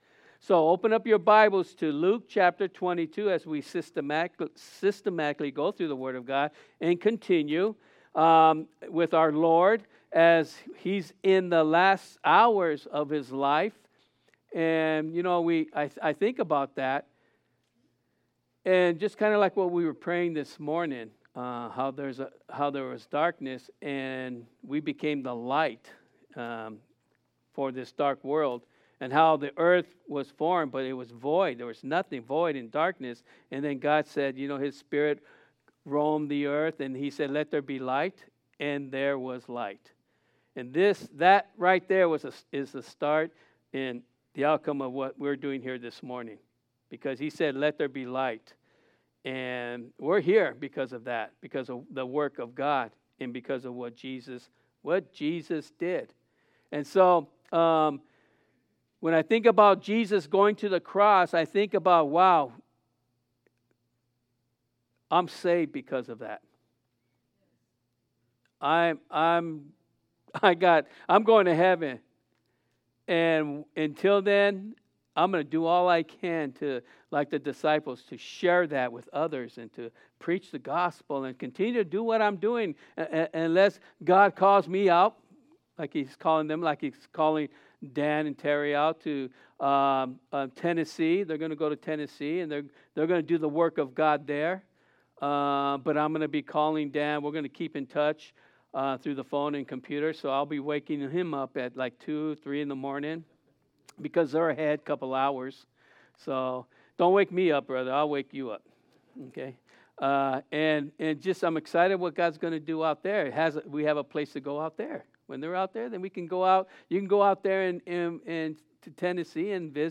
Listen to our audio archive of past Sunday worship services.